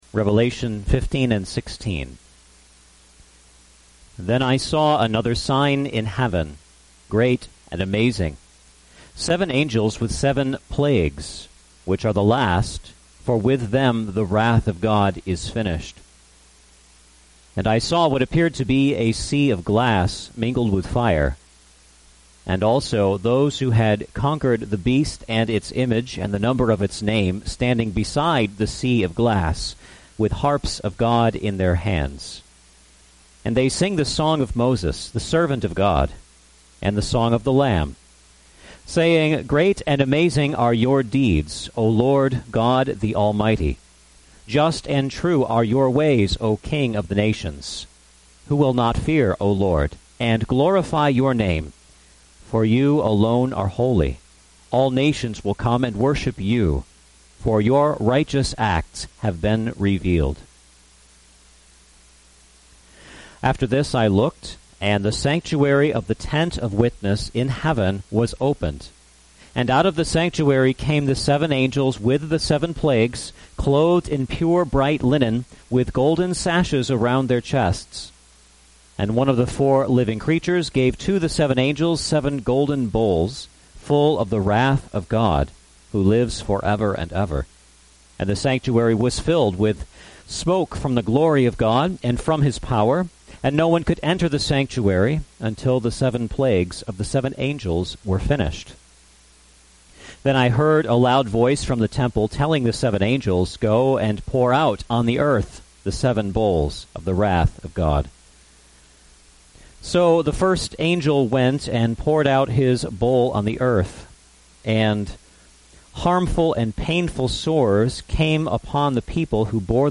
Passage: Revelation 15 – 16 Service Type: Sunday Evening Service Handout